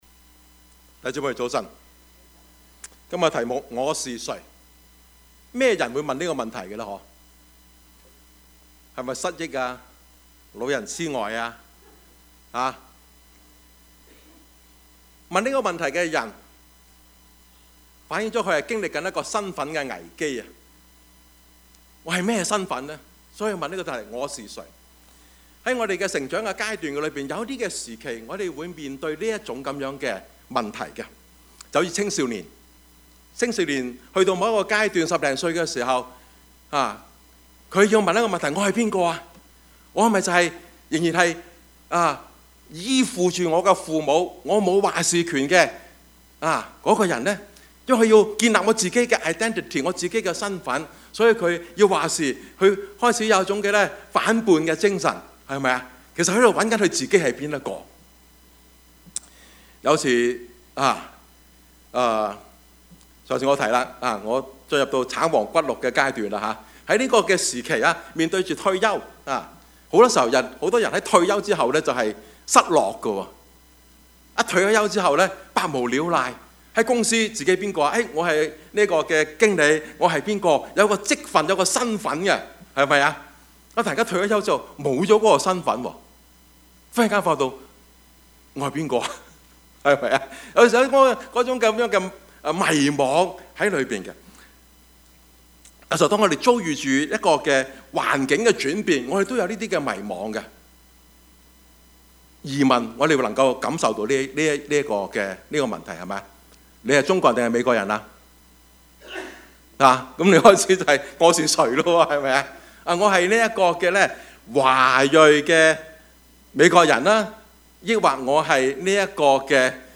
Service Type: 主日崇拜
Topics: 主日證道 « 彼拉多的良心與掙扎 2019年的感恩 »